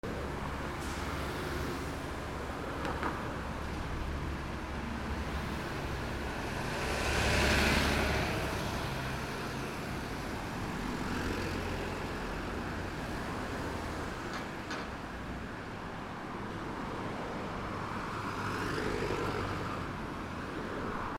バス通過
/ E｜乗り物 / E-35 ｜バス